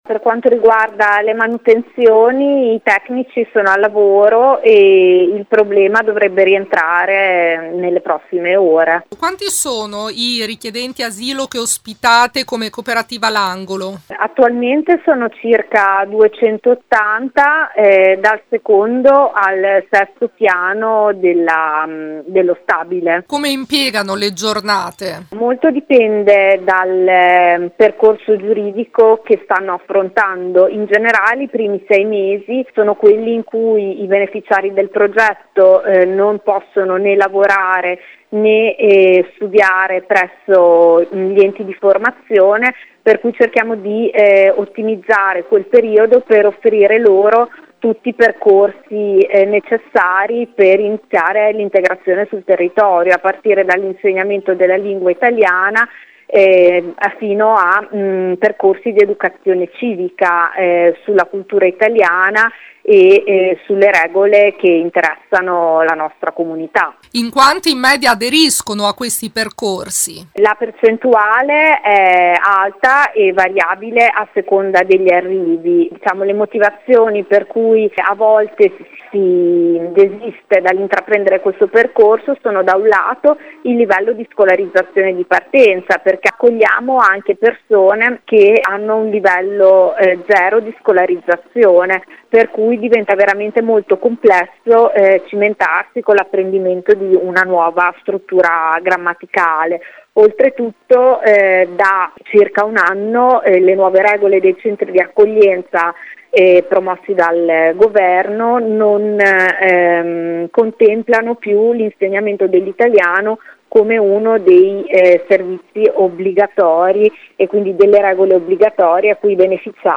L’accoglienza dei richiedenti asilo all’ex studentato: intervista